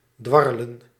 Ääntäminen
IPA : /wɜːl/